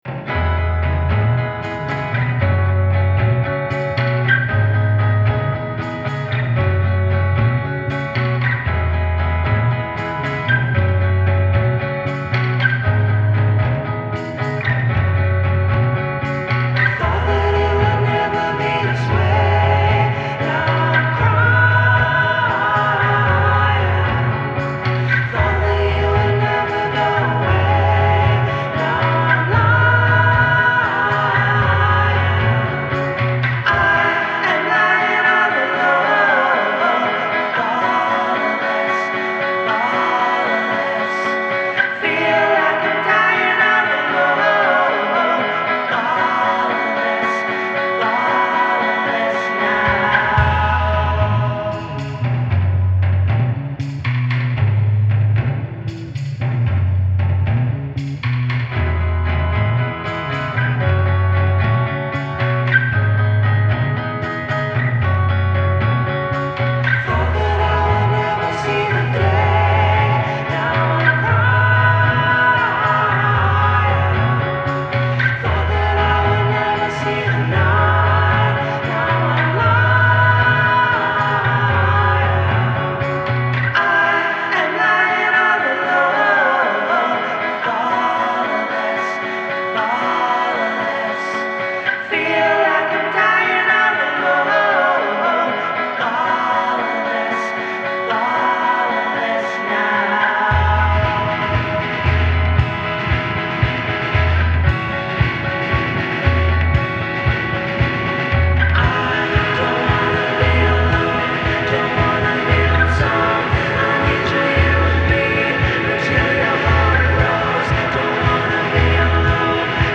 This hazy three-piece